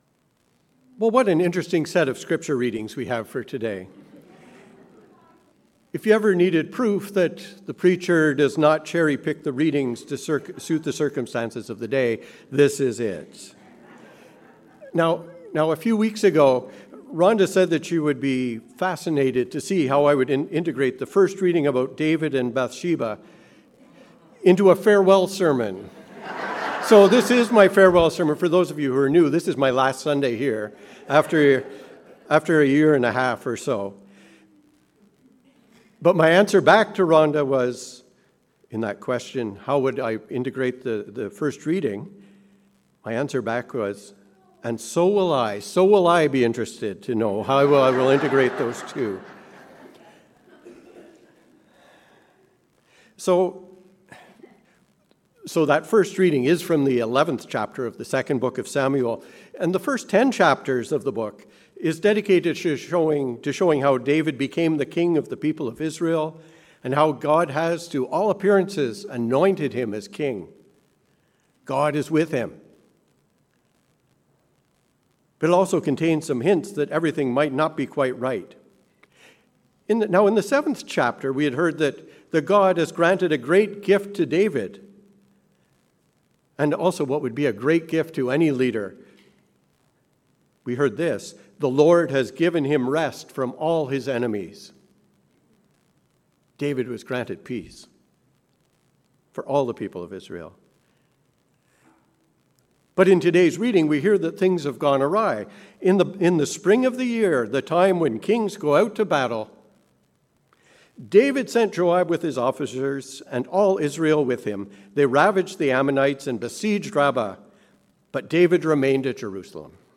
Sermon for the 10th Sunday after Pentecost